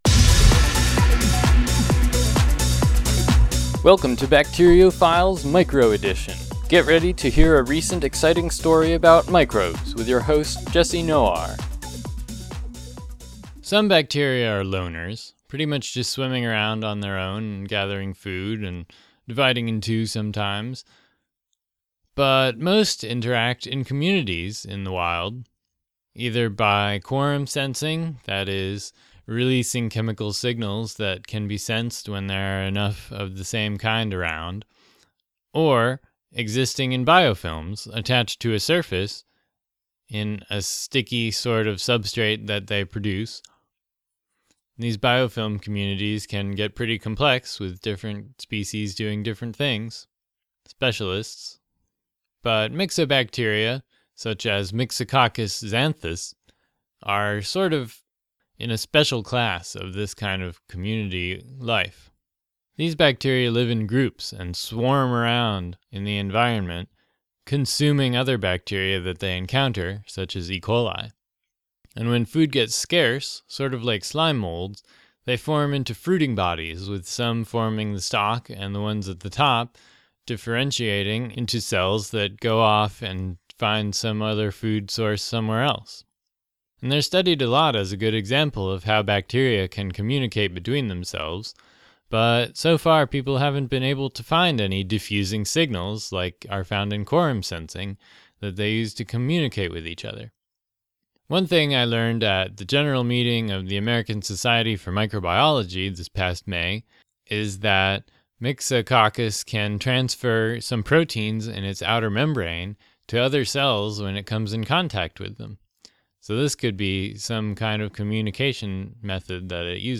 This show features music from Mevio's podsafe Music Alley.